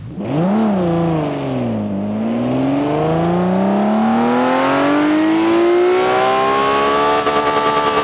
Index of /server/sound/vehicles/lwcars/lam_reventon
rev.wav